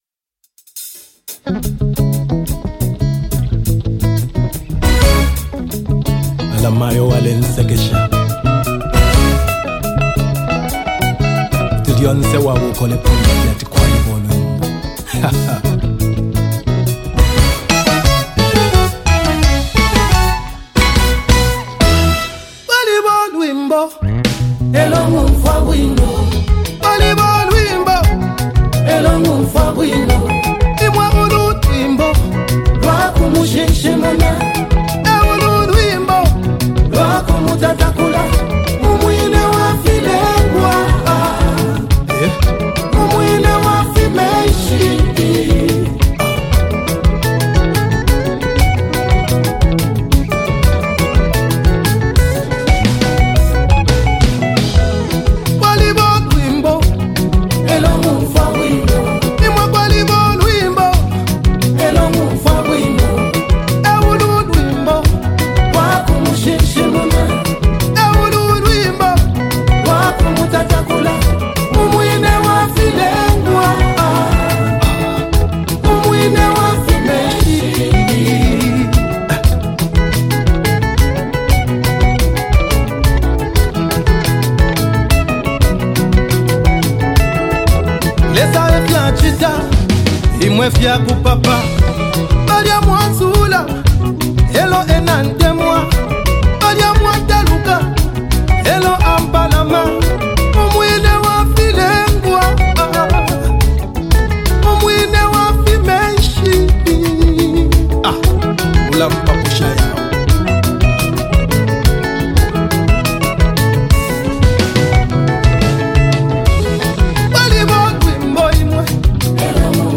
Gospel Music
gospel track